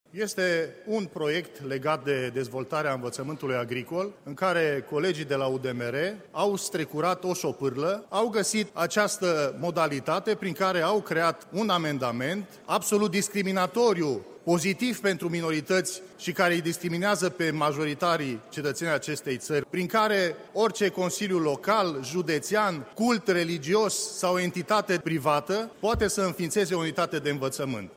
Deputatul PMP, Marius Paşcan, susţine că propunerea UDMR reprezintă, de fapt, o încercare mascată de înfiinţare a Liceului Romano-Catolic de la Târgu-Mureş, după ce legea care viza acest lucru a fost declarată neconstituţională: